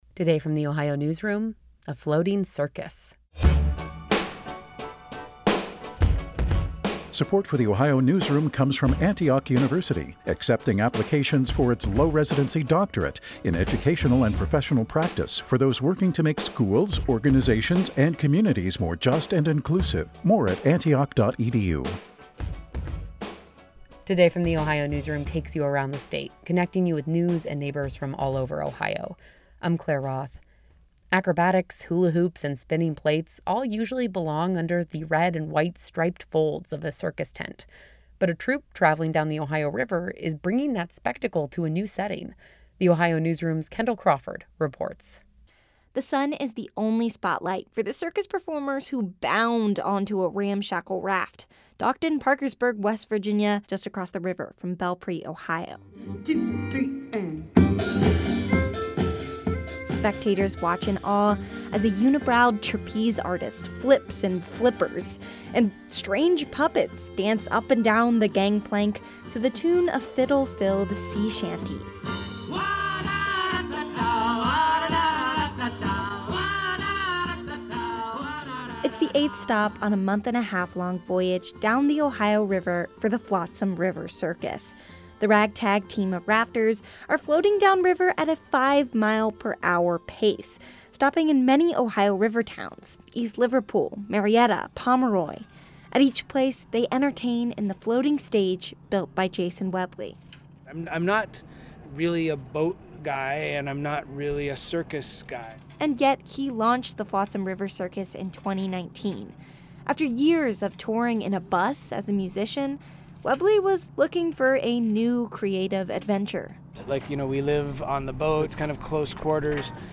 A crowd of more than a hundred spectators watch in awe as a unibrowed trapeze artist flips in flippers, clownish sea captains attempt daring feats and strange puppets dance up and down the gangplank to the tune of fiddle-filled sea shanties.